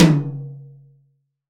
Index of /90_sSampleCDs/AKAI S6000 CD-ROM - Volume 3/Drum_Kit/DRY_KIT2